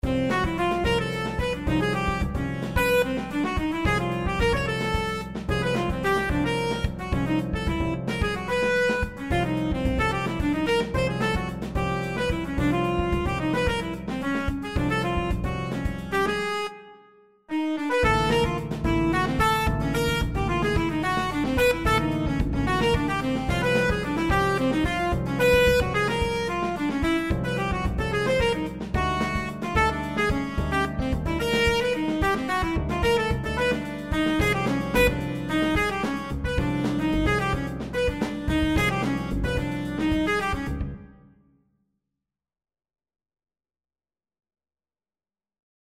Composing Using Tone Rows